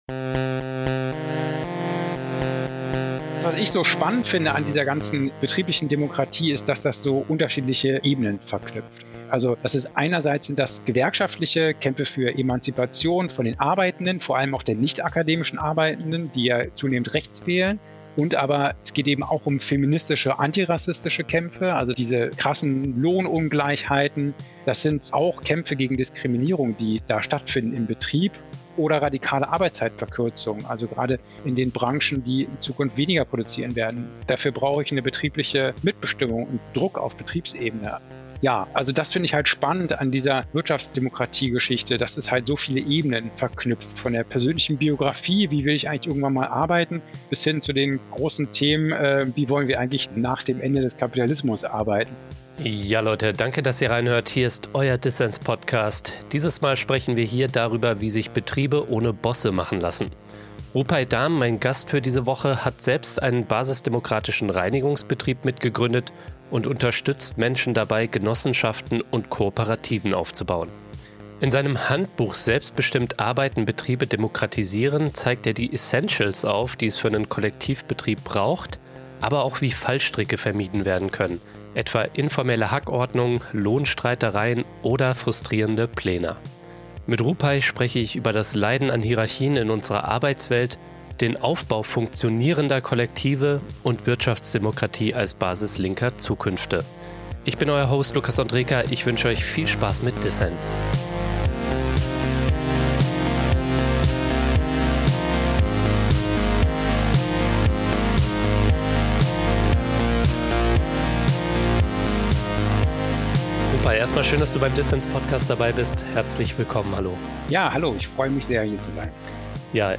Das Interview ist der erste Teil unserer Miniserie zum Erfolg der Linkspartei.